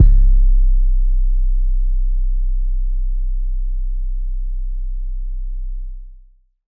BWheezy 808.wav